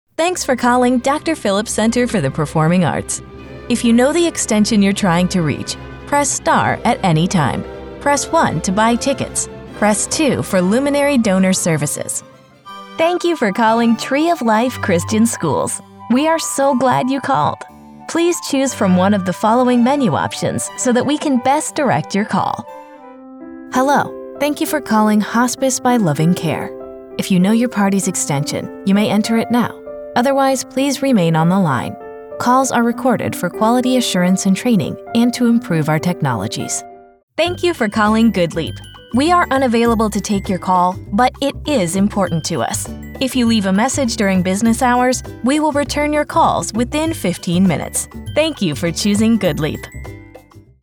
Commercial, Natural, Friendly, Warm, Corporate
Telephony